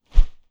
Close Combat Swing Sound 10.wav